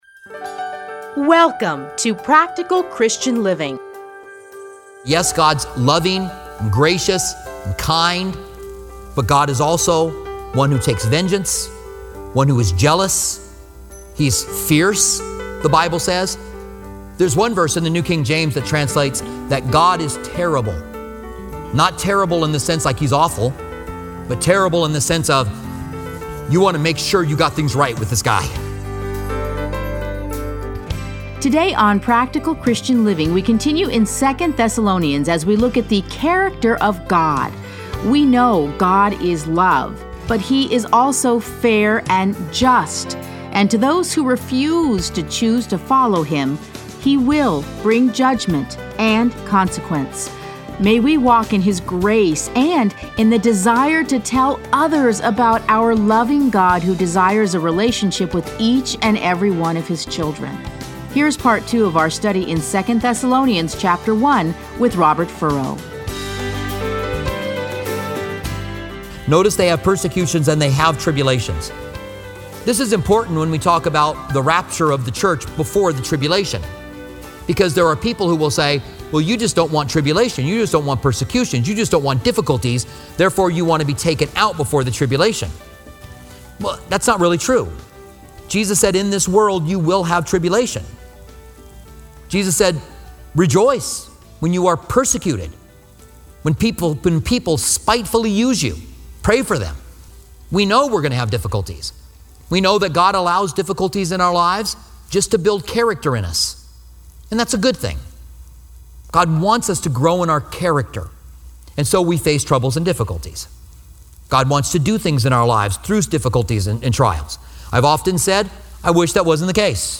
Listen to a teaching from 2 Thessalonians 1:1-12.